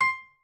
pianoadrib1_60.ogg